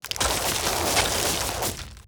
Ice Barrage 1.wav